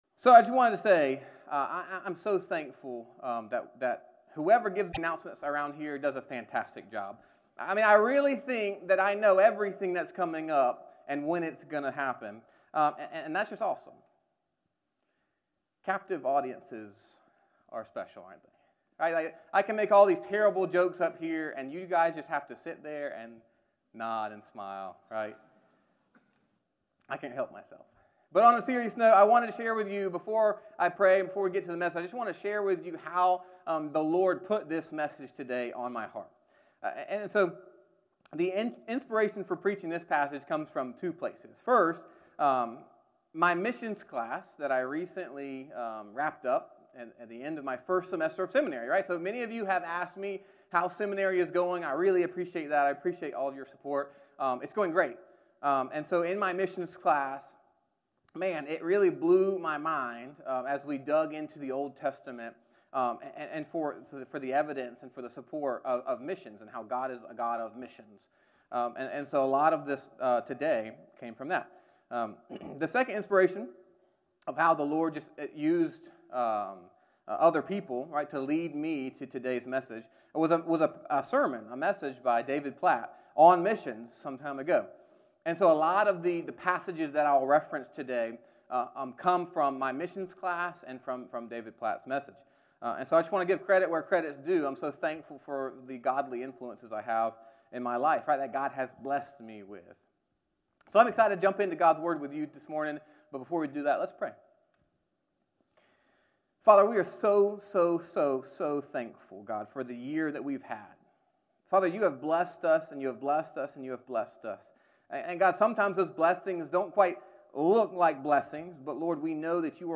Sermons - First Baptist Church of Shallotte